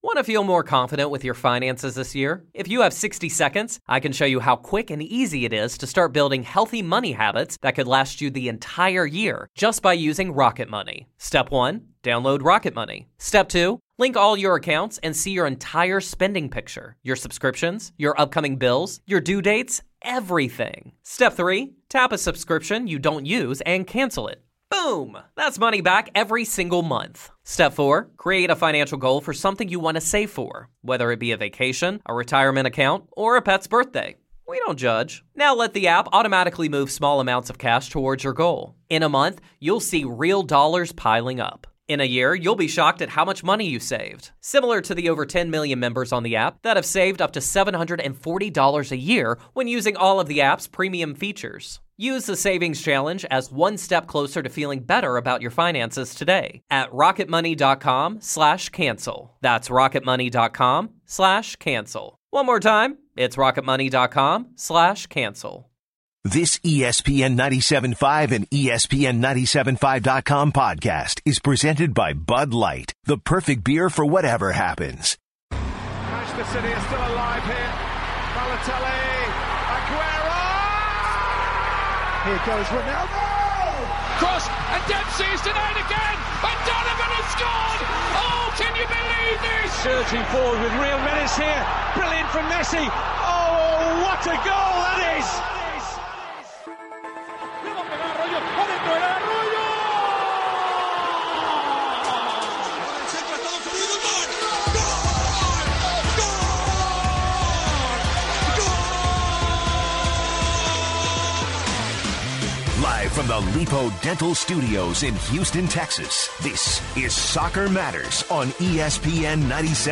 Callers chime in on the upcoming Dynamo vs. FC Dallas game, Barcelona and more!